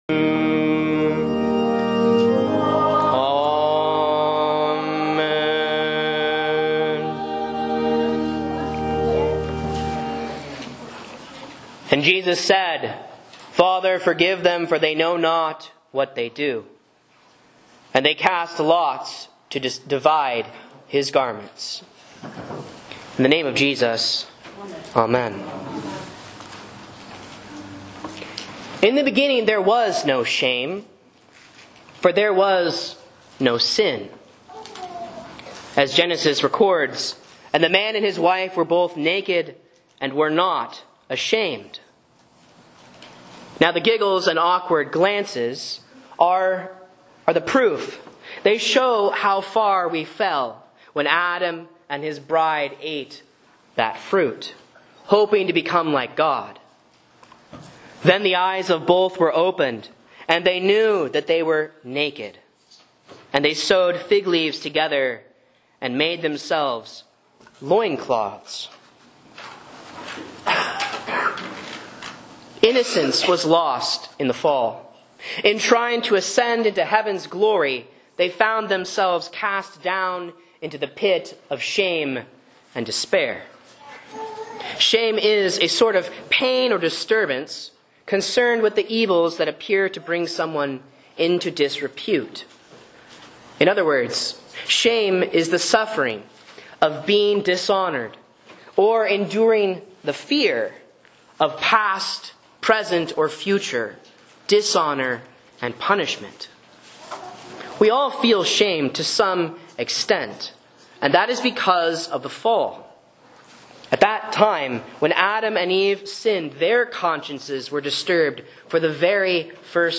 Sermons and Lessons from Faith Lutheran Church, Rogue River, OR
A Sermon on Luke 23.34 for Palm/Passion Sunday (C)